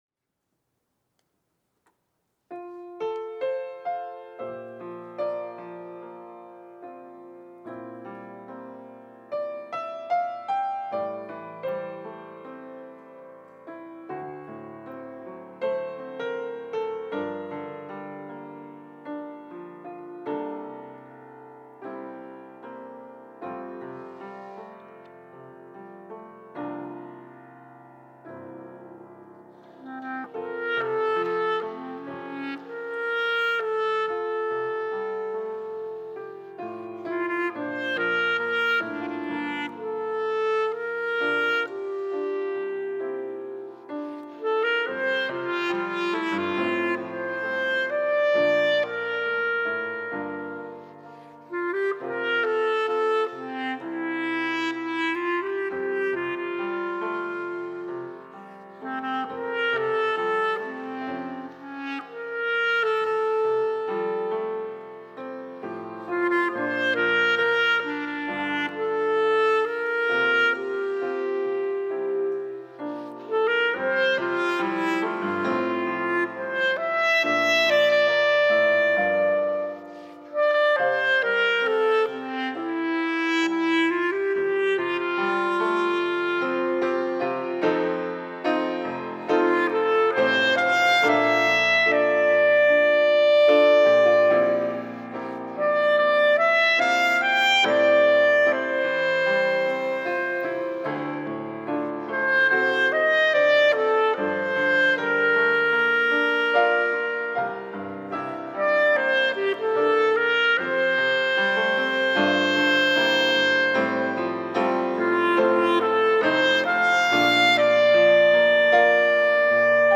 특송과 특주 - 하나님의 은혜